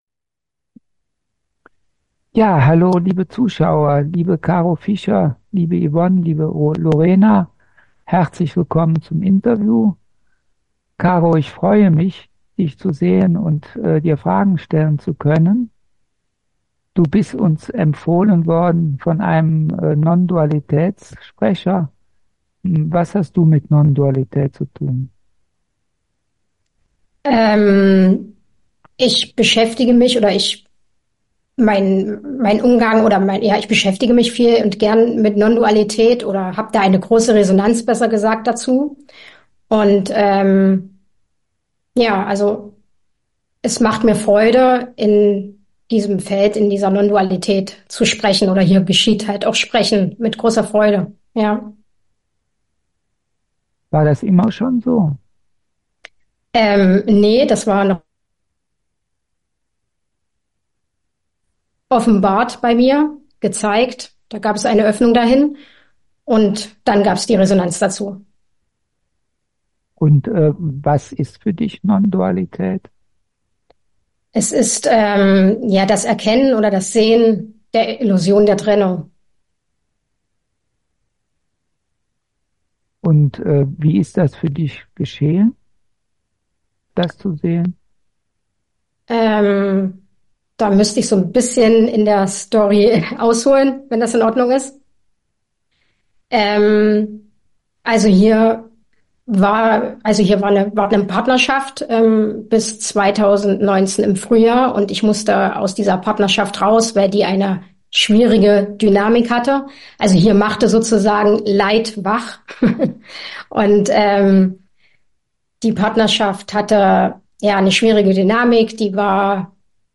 Interview01